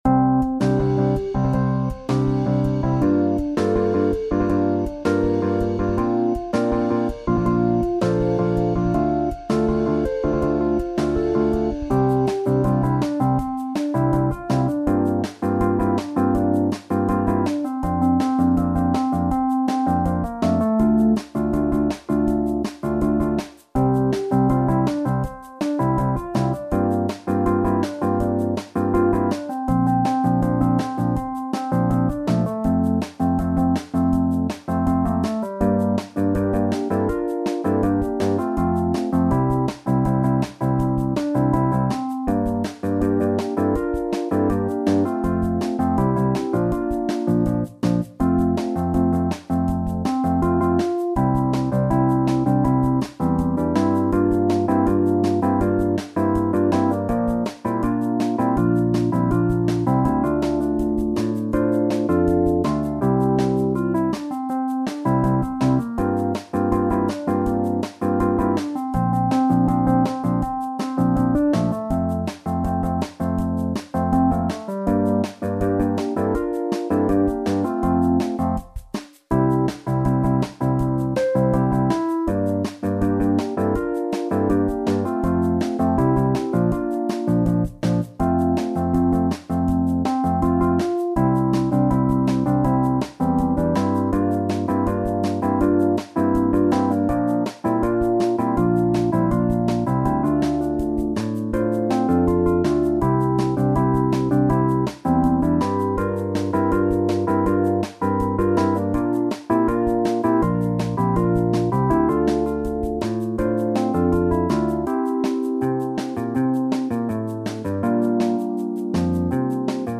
SSAATB | SSATTB | SSATB
Een lekker stevig nummer